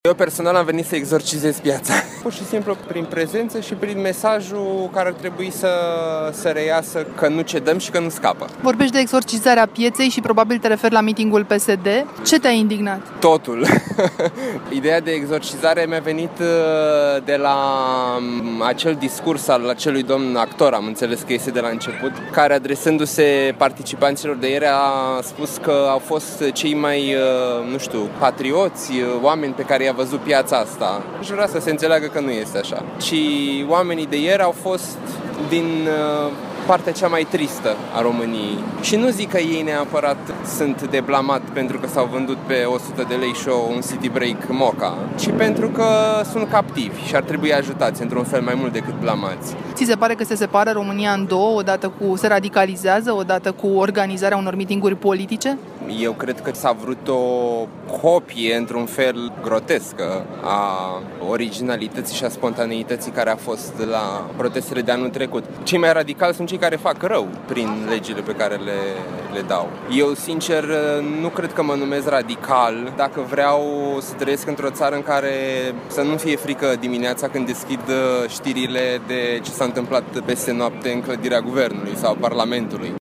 Flutură steaguri, suflă în vuvuzele și strigă lozinci antiguvernamentale – așa arată la această oră mitingul împotriva Guvernului care are loc duminică seara în Piața Victoriei.
Ce spun oamenii din Piața Victoriei?